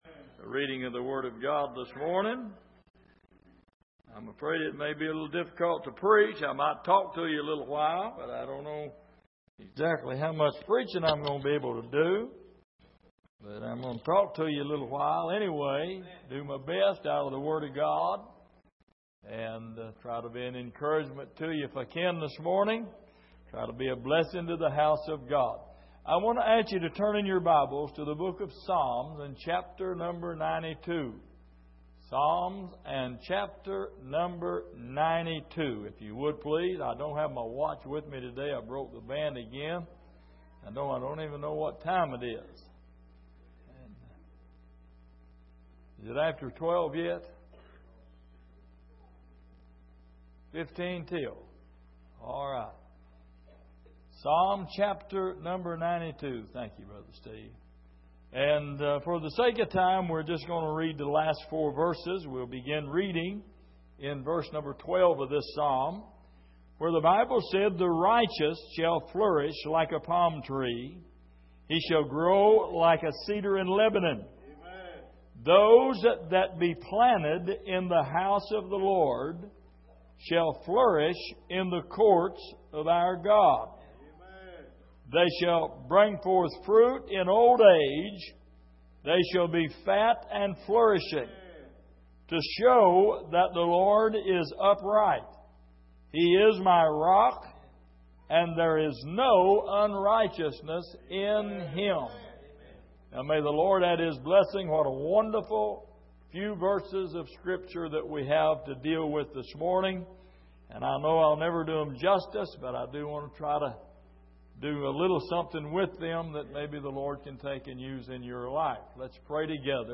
Passage: Psalm 92:12-15 Service: Sunday Morning